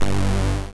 synth9.wav